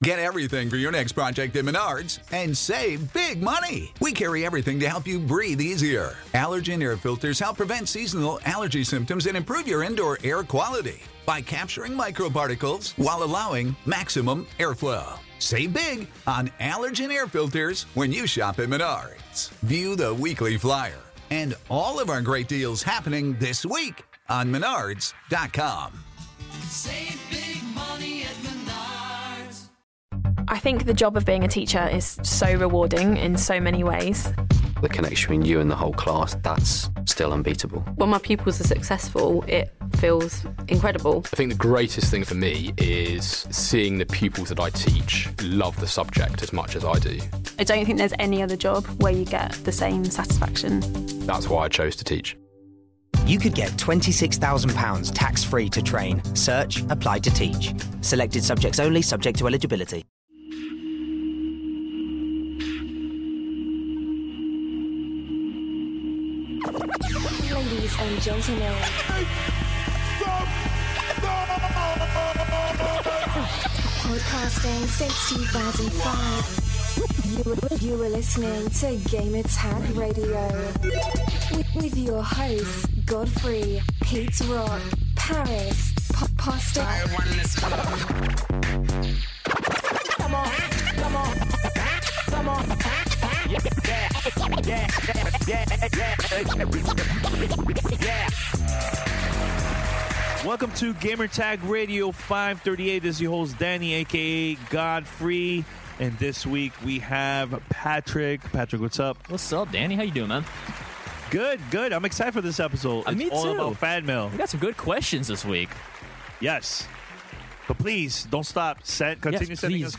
Episode #538 - Fan Mail and Severed Interview